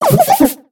Cri de Couverdure dans Pokémon X et Y.